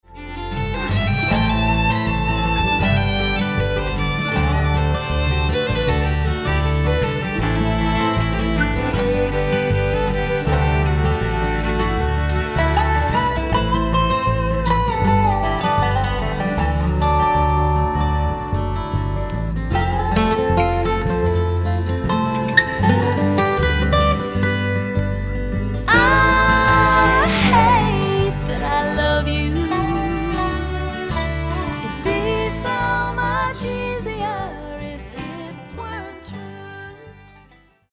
Back up Vocals